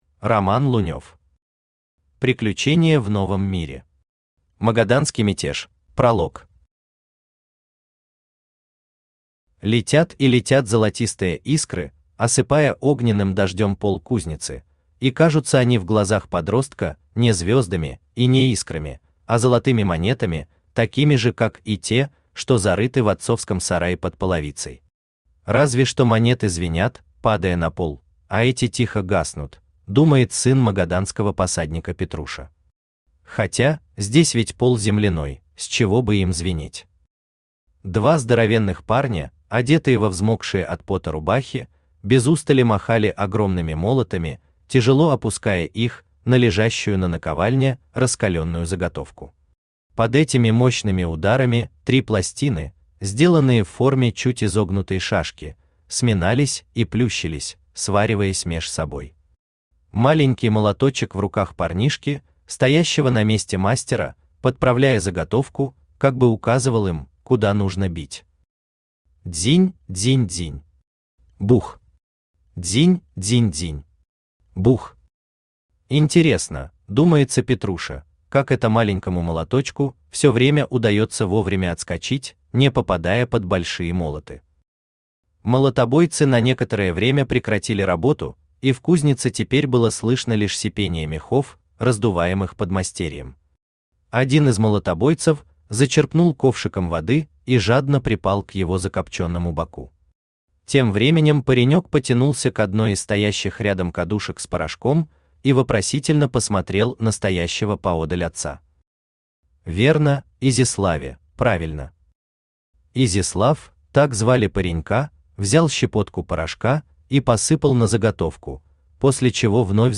Аудиокнига Приключения в Новом мире. Магаданский мятеж | Библиотека аудиокниг